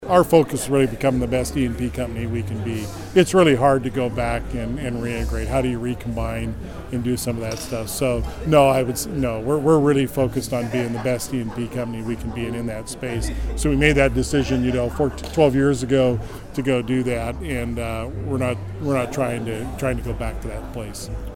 Lance was the guest speaker during a Bartlesville Chamber of Commerce forum on Tuesday at CityChurch.